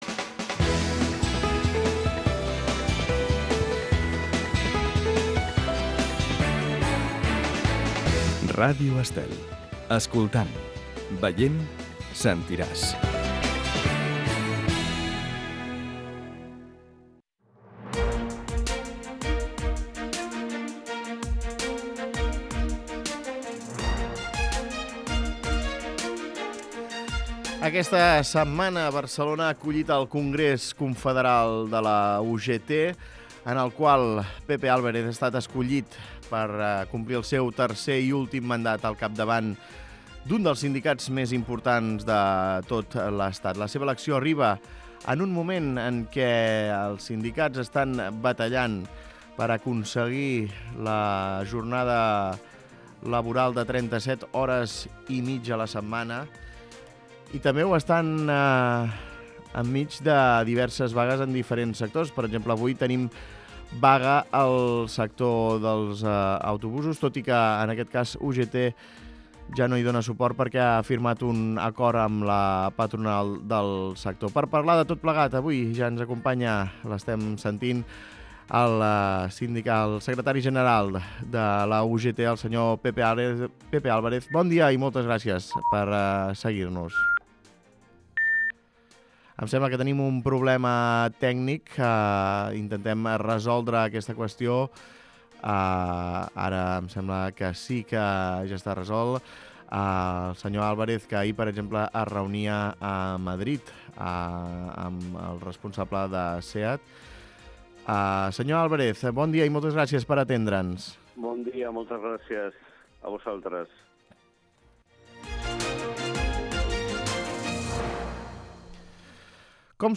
Escolta l'entrevista a Pepe Álvarez, secretari general de la UGT